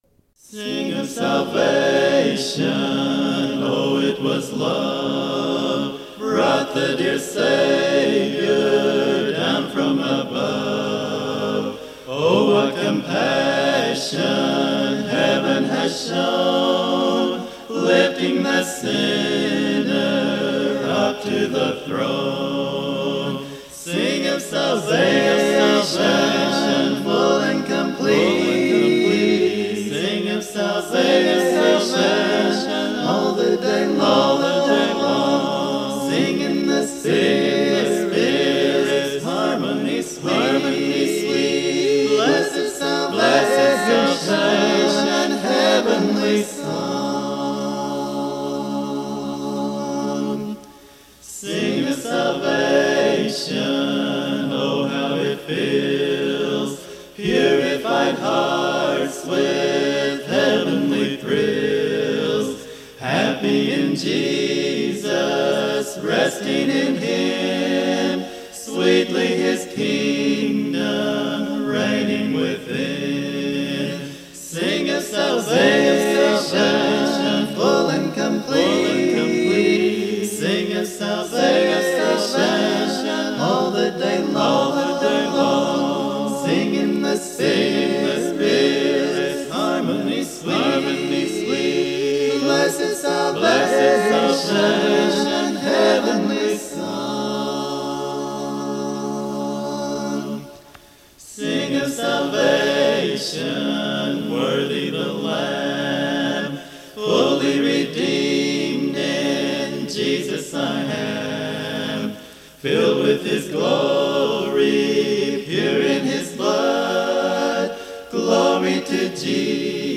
Key: C